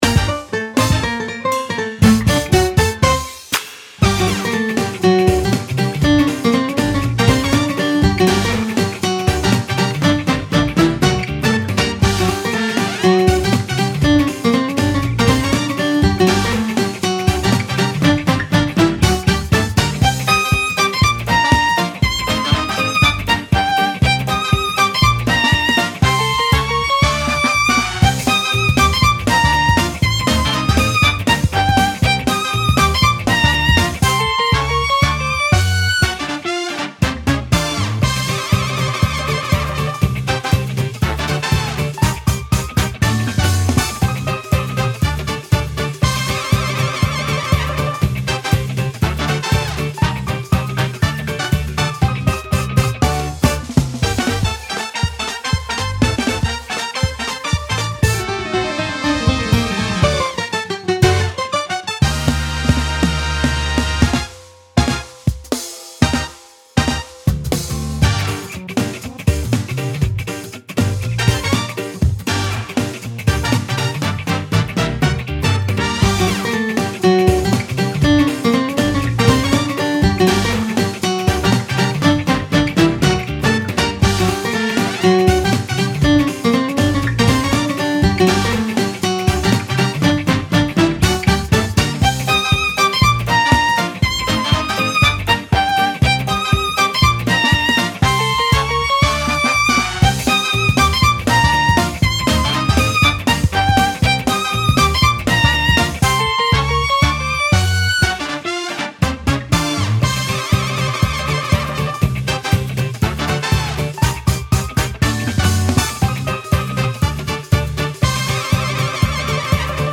このフリーBGMはコンセプトとして、「カジノを感じさせるノリの良い曲」を掲げてます。
トランペットなどの金管楽器を使ったノリの良いBGMって時々作りたくなるんですよね。